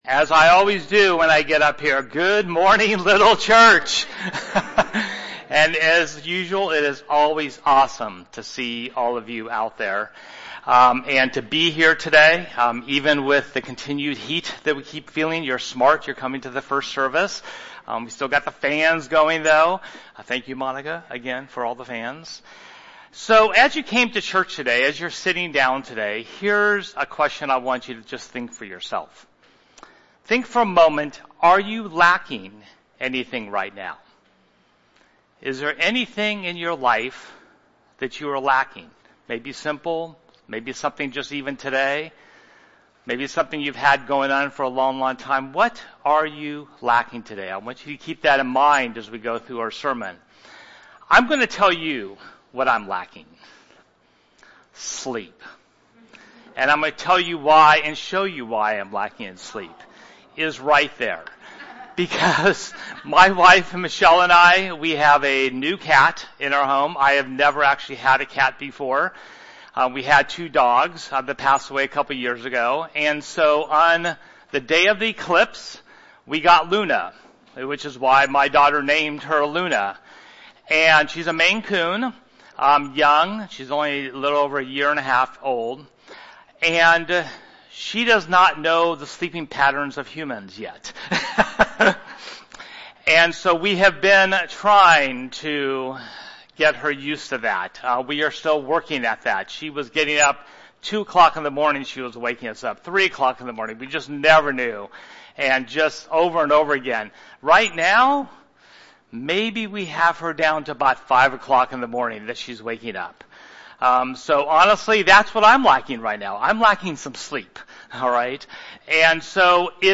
Series: Ordinary Time